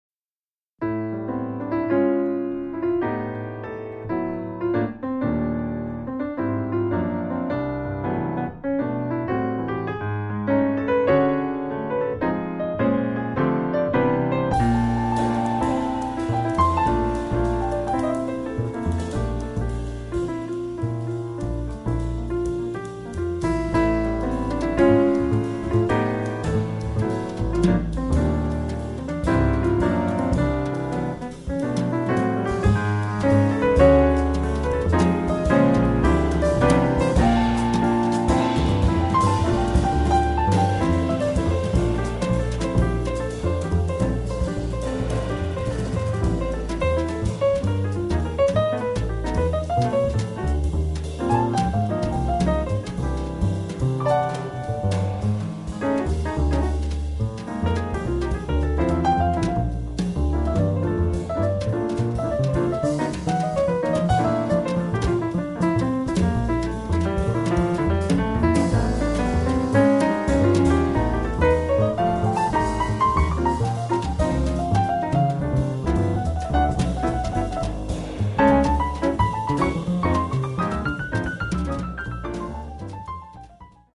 piano
contrabbasso
batteria
lirica ed evocativa